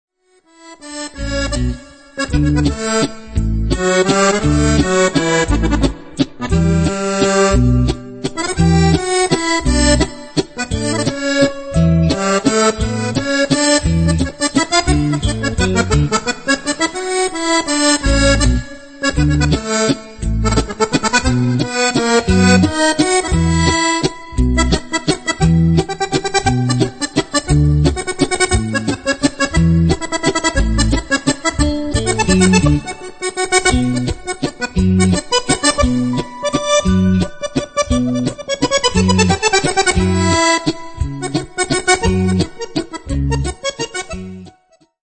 valzer